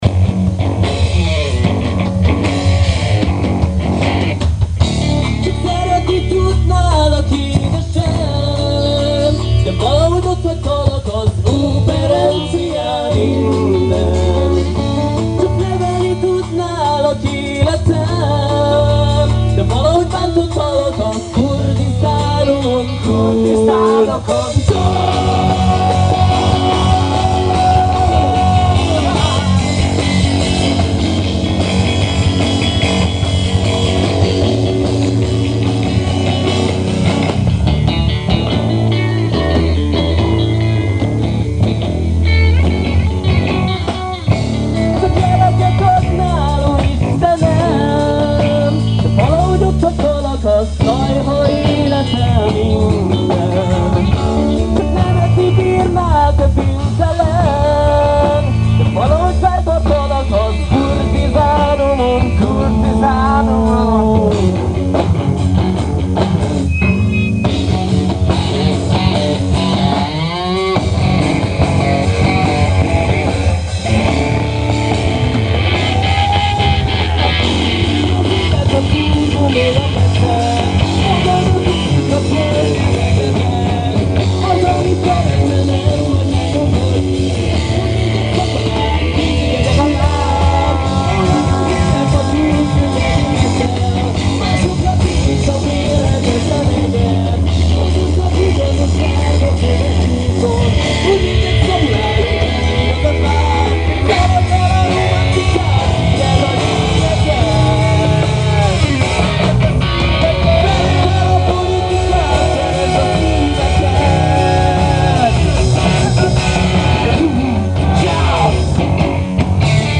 Néhány koncertfelvétel: